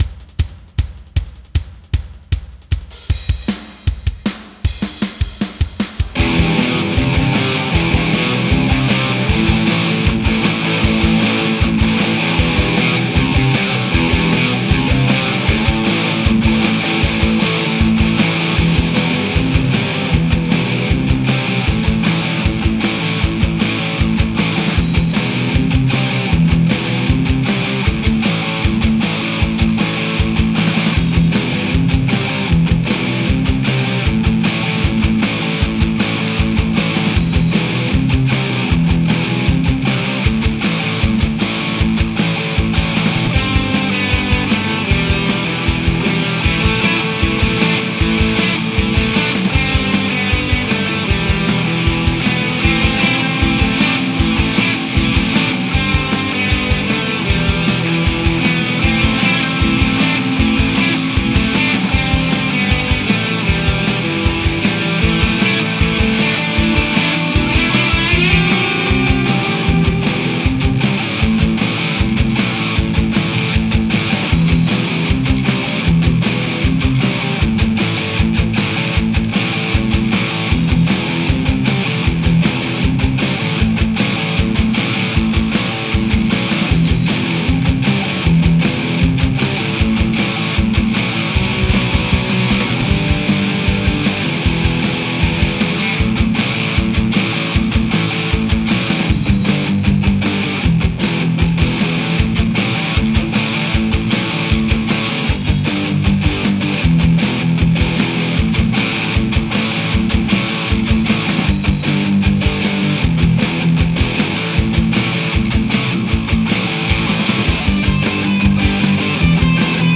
Тест лампового эква А этот файл без лампы